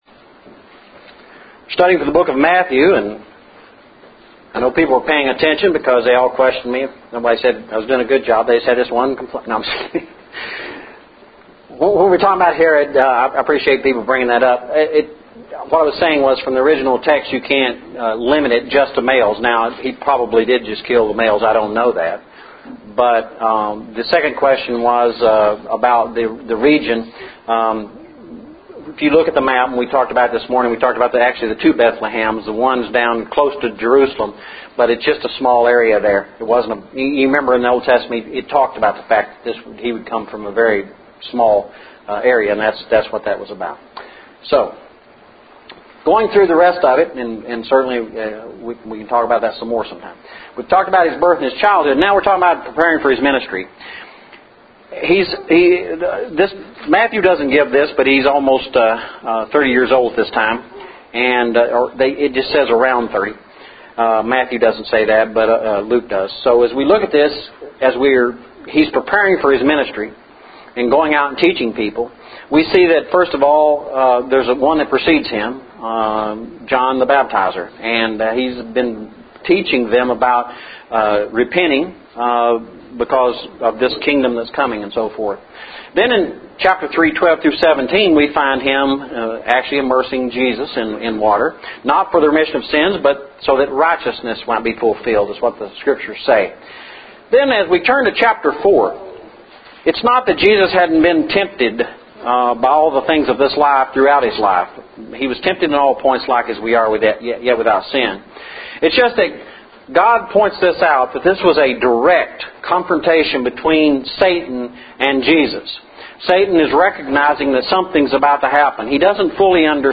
The Book of Matthew Lesson – 08/28/11